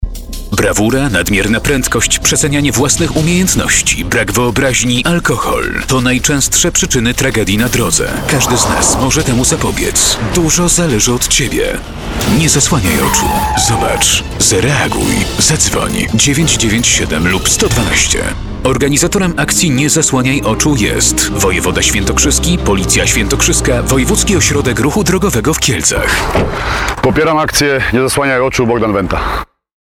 "Nie zasłaniaj oczu" - spot radiowy
niezaslaniajoczu-radio.mp3